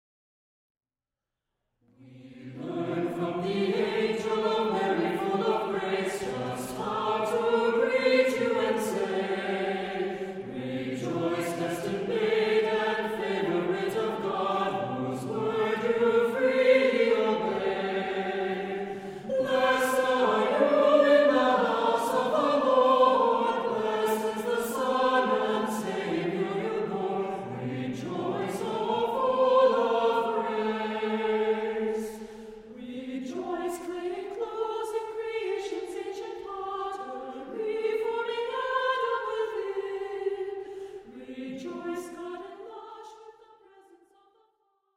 arr. Galician Carol